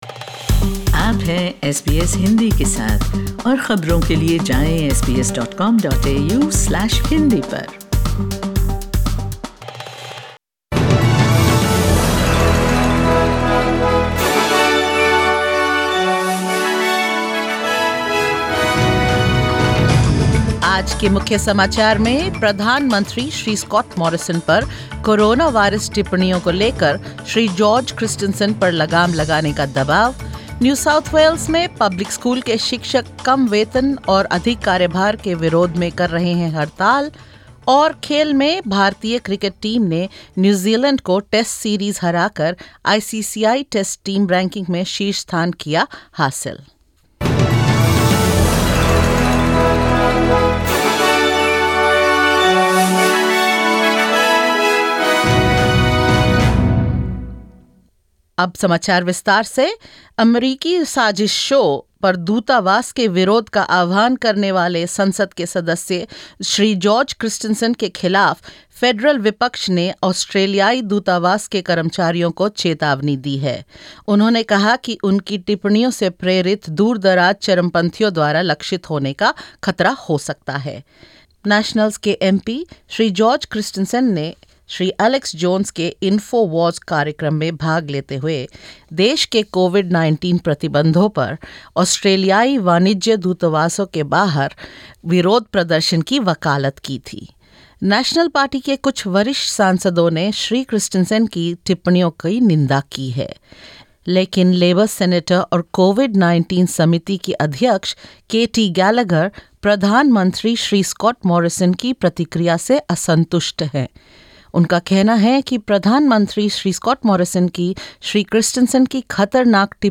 In this latest SBS Hindi news bulletin of Australia and India: Prime Minister Morrison criticized over lack of action on George Christensen’s coronavirus comments; New South Wales public school teachers protest against low salaries and unsustainable workload; India beats New Zealand to reclaim the top spot in the International Cricket Council test ranking and more.